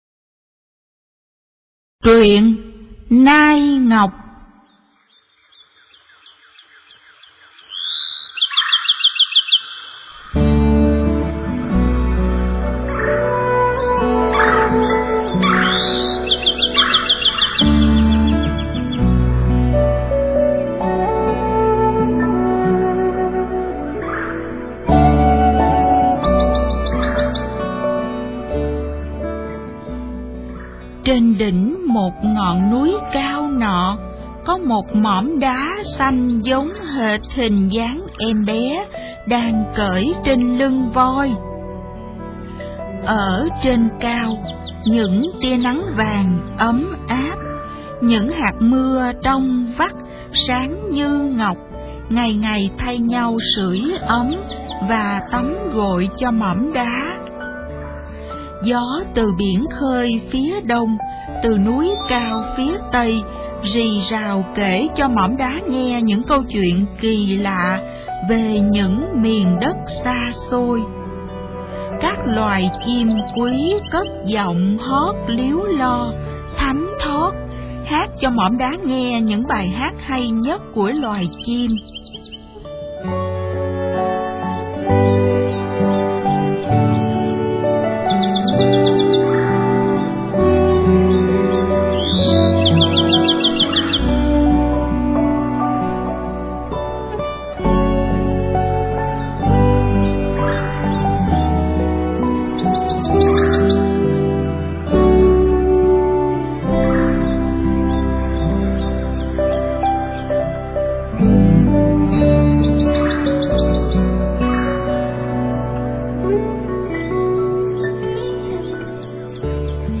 Sách nói | Sự Tích Hồ Ba Bể